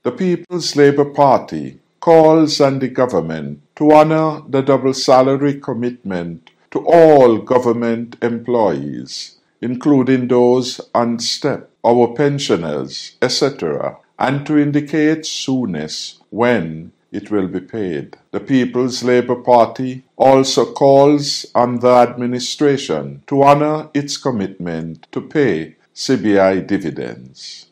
That was Former Prime Minister and Leader of the People’s Labour Party (PLP), Hon. Dr. Timothy Harris during an address to the nation.